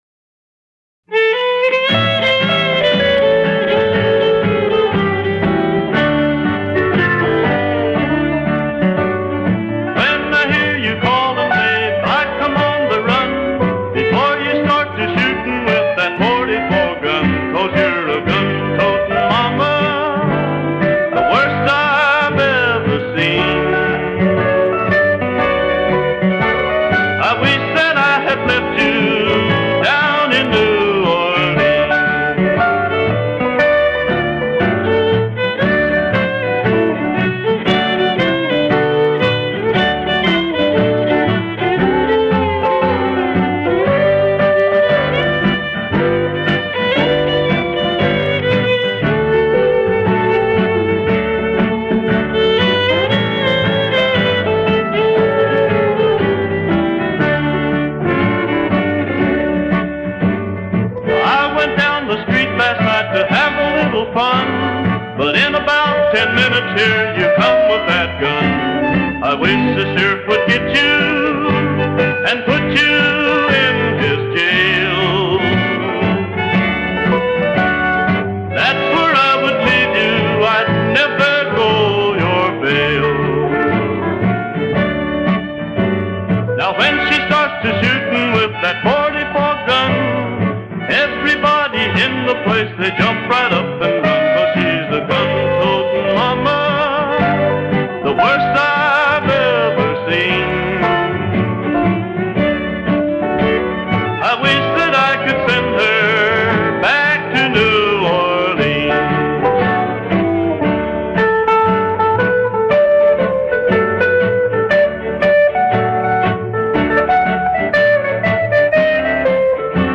from this Honky-Tonk style singer, songwriter, guitarist